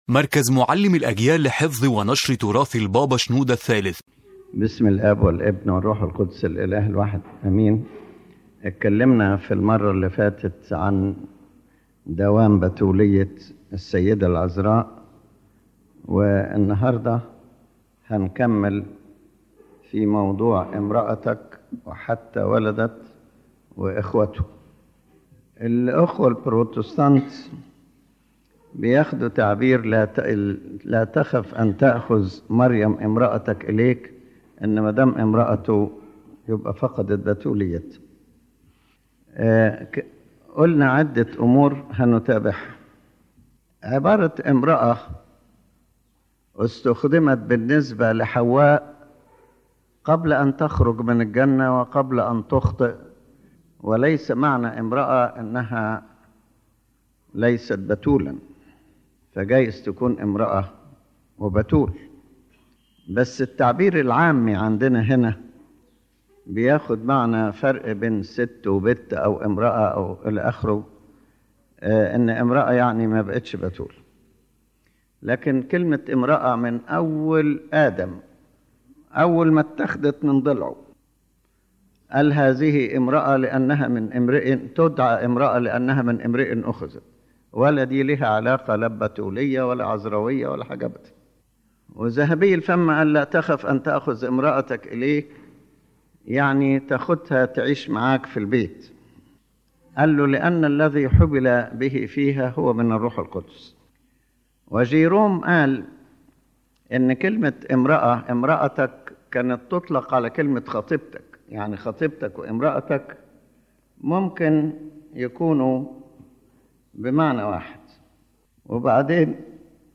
First: The Main Idea of the Lecture